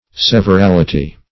Search Result for " severality" : The Collaborative International Dictionary of English v.0.48: Severality \Sev`er*al"i*ty\, n.; pl.